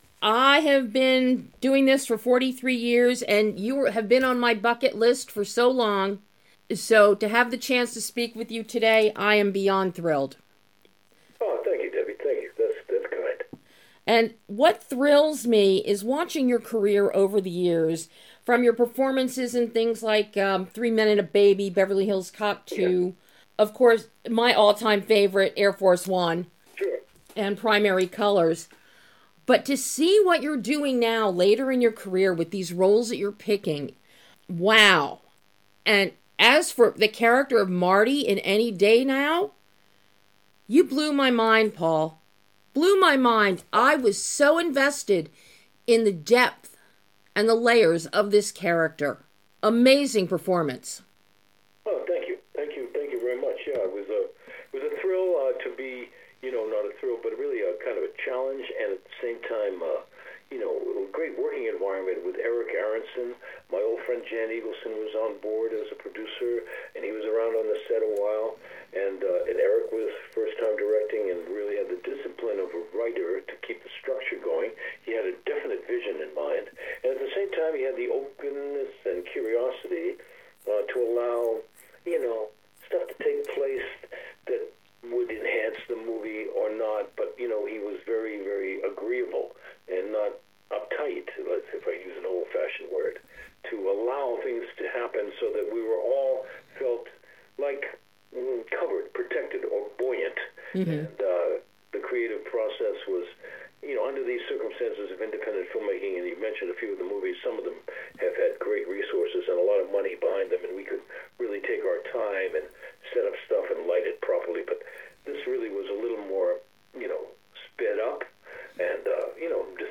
A thoughtful and insightful interview with PAUL GUILFOYLE discussing his latest film, ANY DAY NOW, and a few other things about the state of the industry today.